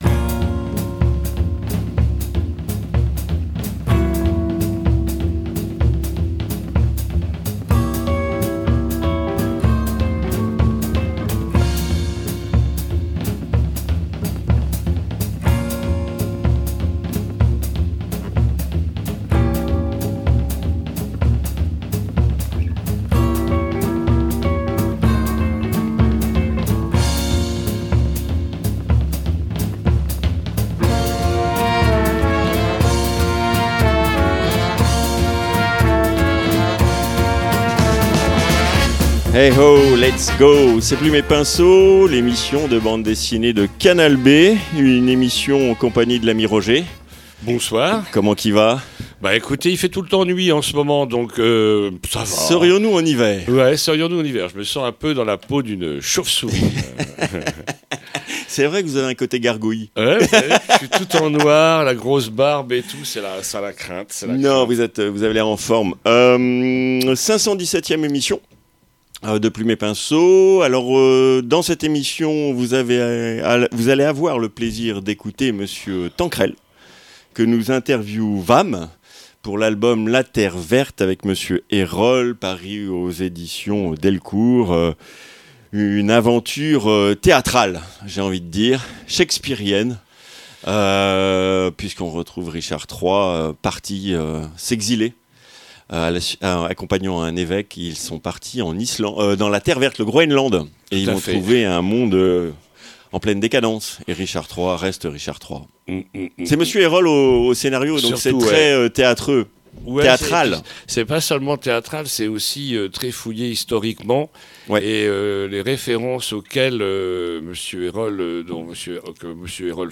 I - INTERVIEW